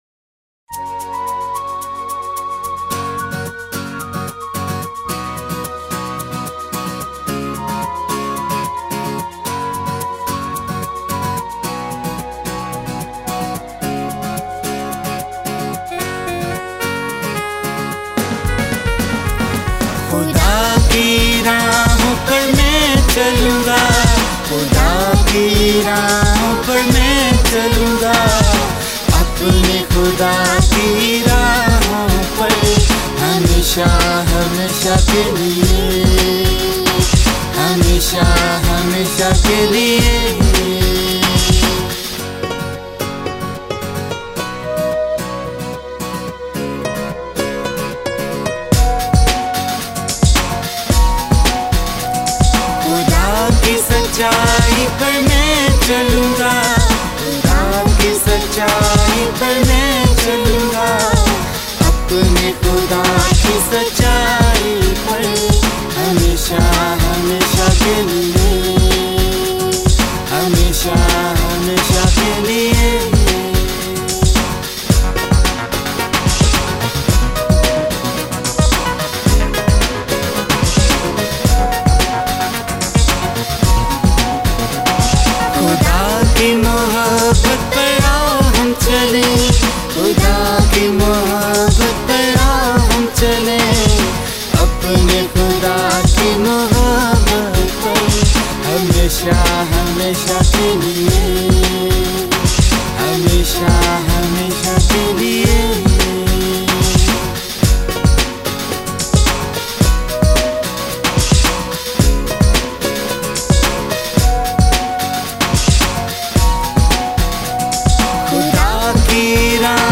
Hear this song in Urdu !!